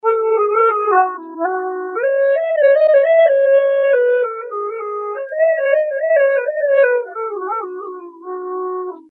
漂亮的爵士乐长笛循环播放，适合爵士乐创作
Tag: 79 bpm Jazz Loops Flute Loops 1.53 MB wav Key : Unknown